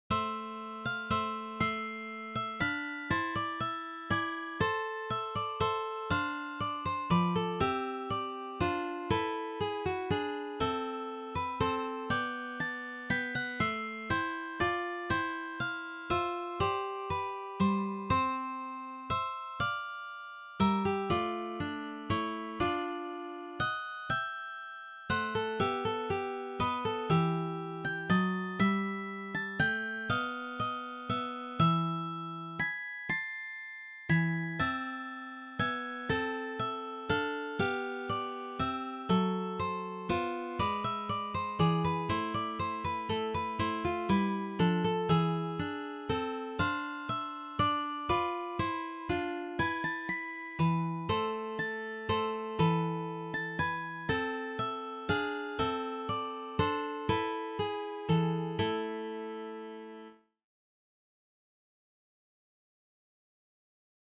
for three guitars